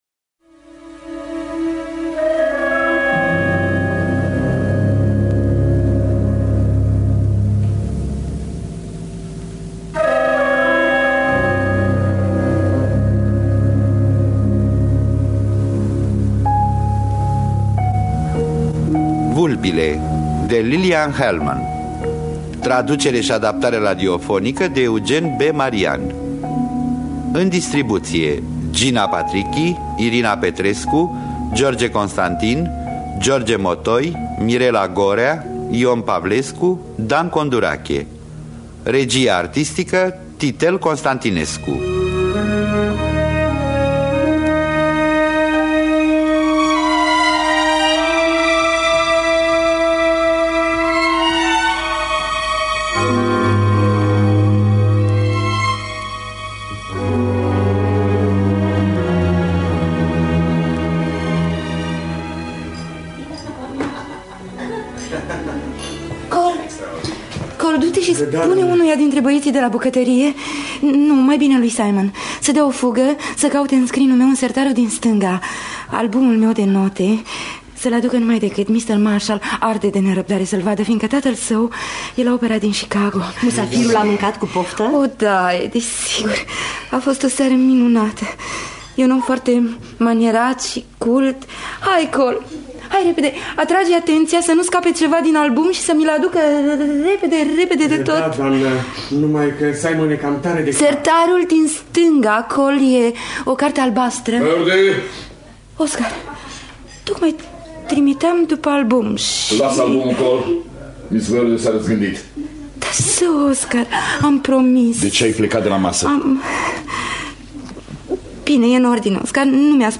Lillian Hellman – Vulpile (1985) – Teatru Radiofonic Online
Traducerea și adaptarea radiofonică